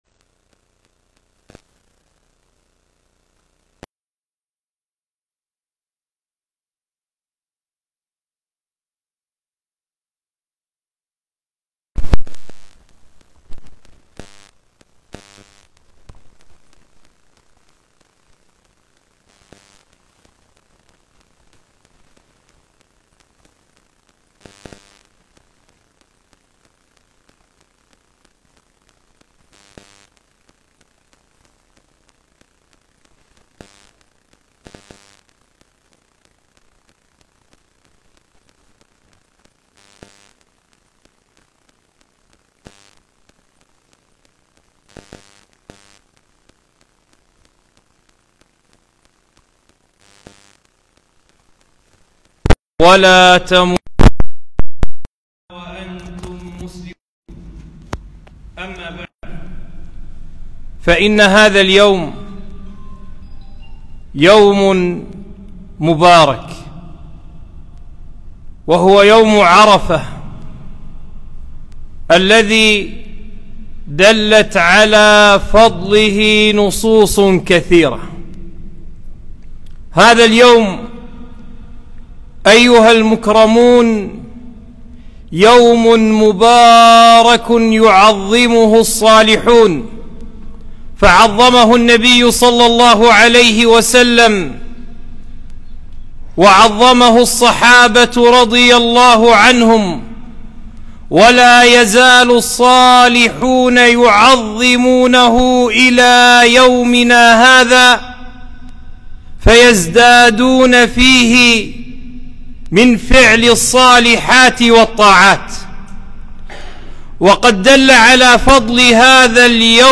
خطبة - يوم عرفة وفضائله